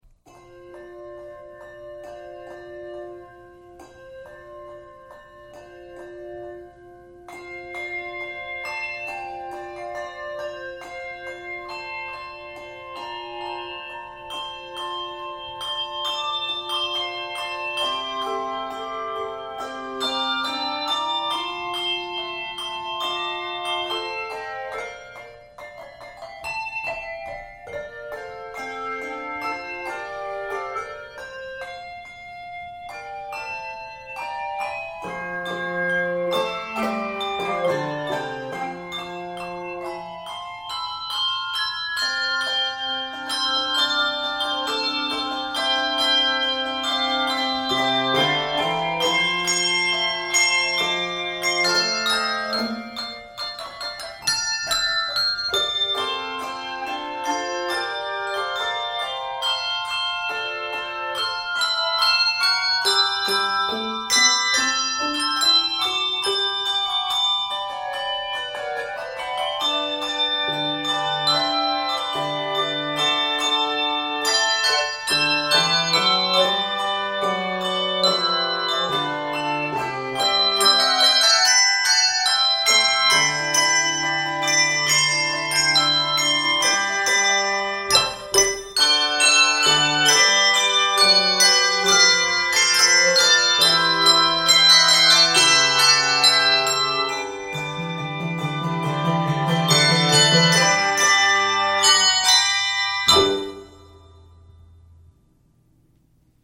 Keys of F Major and G Major.
Spiritual Arranger
Octaves: 3-5